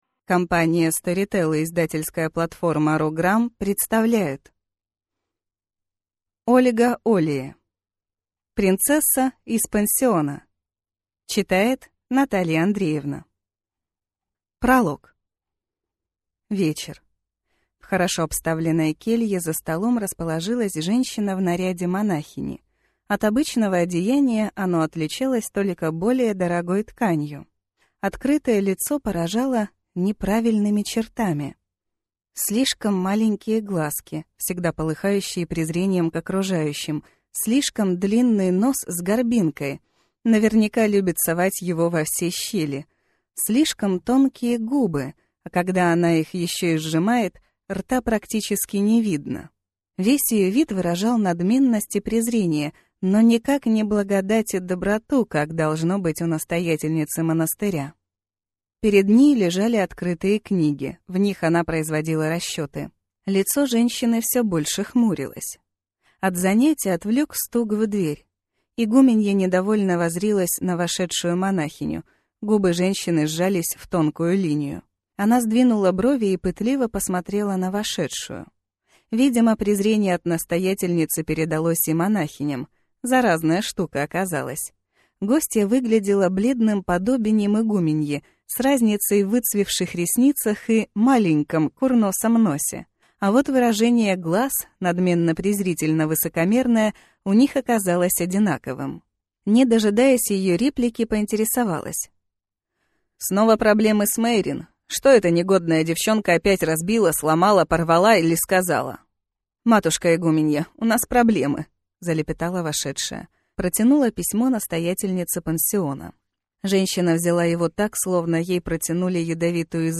Аудиокнига Принцесса из пансиона | Библиотека аудиокниг